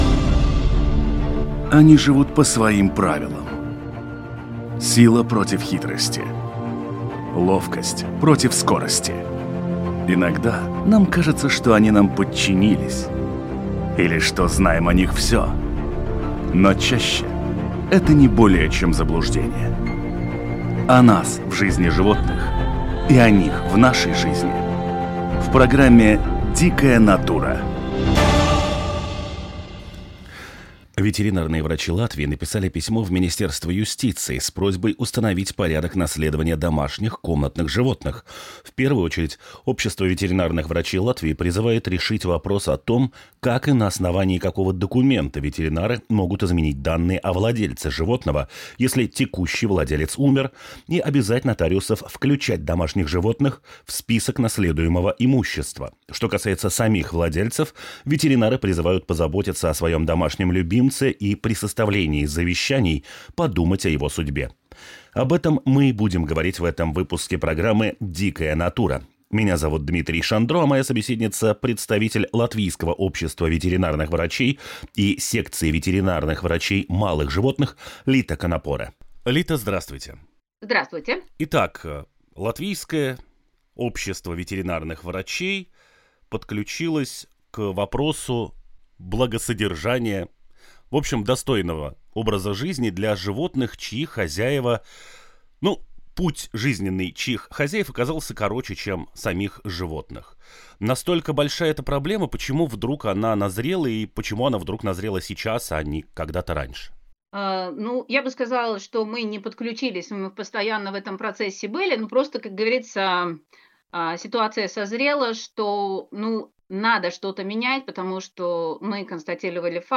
Наш гость - эксперт по латвийской фауне, гидробиолог